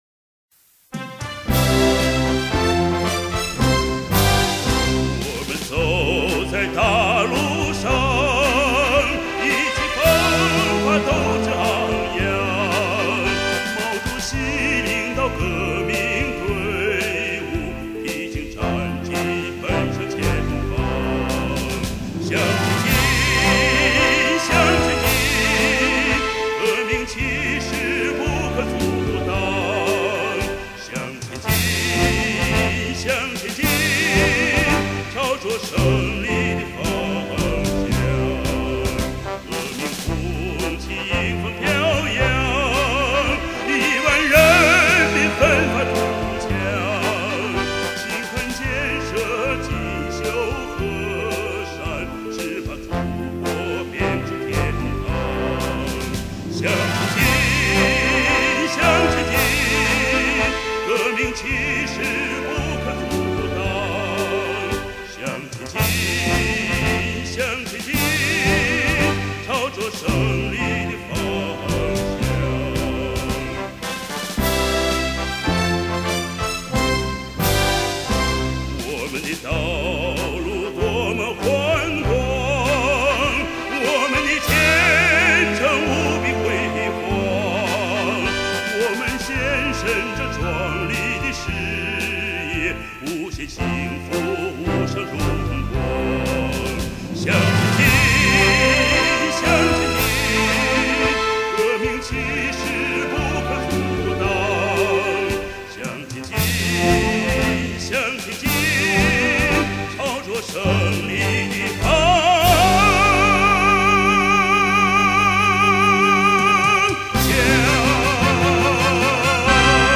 作为国内首屈一指的男中音歌唱家，凭其出色的演唱造诣夺得了全国最高的文华奖和梅花奖。